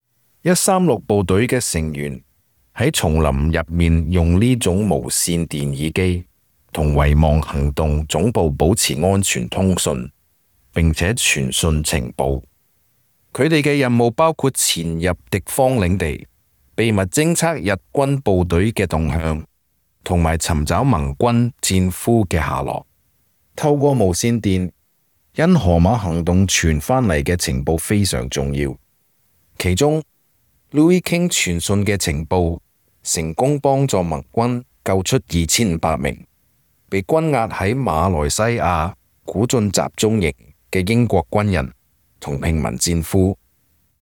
Special Operations Voiceovers
2_CANTO_Radio_Headset_voiceover__eq_.mp3